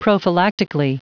Prononciation du mot prophylactically en anglais (fichier audio)
Prononciation du mot : prophylactically
prophylactically.wav